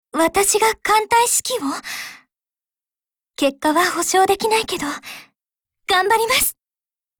Cv-39904_warcry.mp3